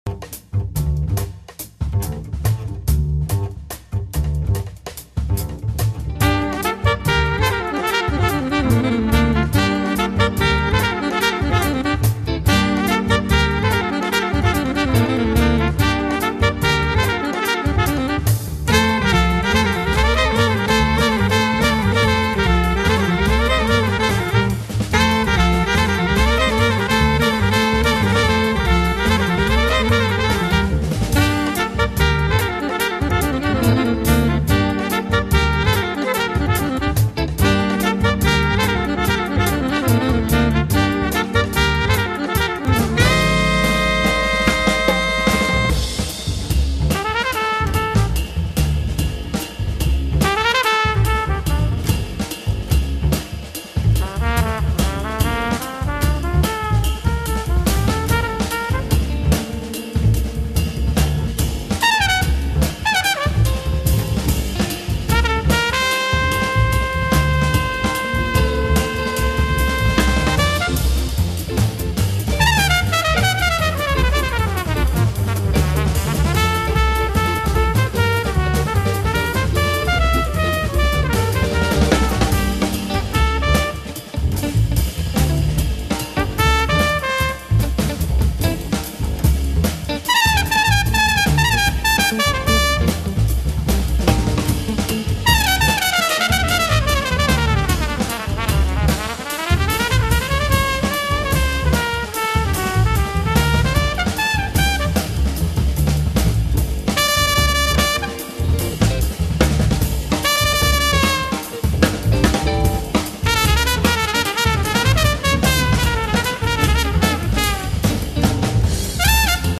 tromba
sax alto e soprano
chitarre
contrabbasso
batteria